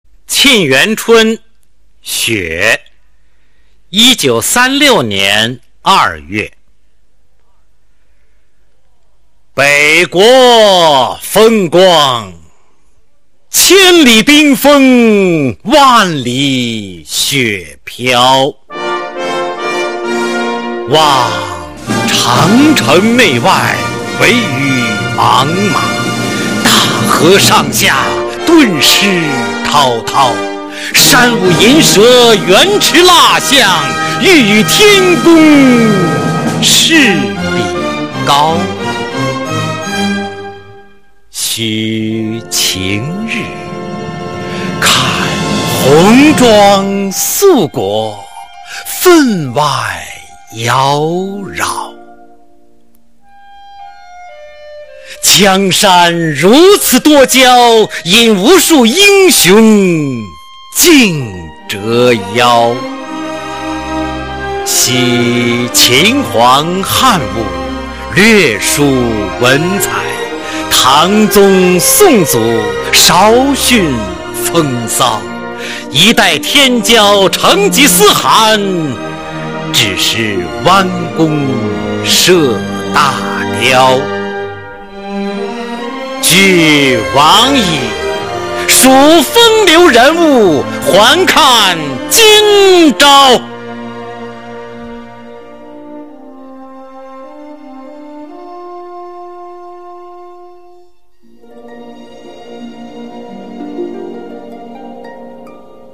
《沁园春·雪》原文和译文（含赏析、mp3朗读）